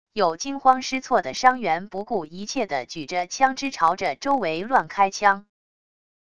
有惊慌失措的伤员不顾一切的举着枪支朝着周围乱开枪wav音频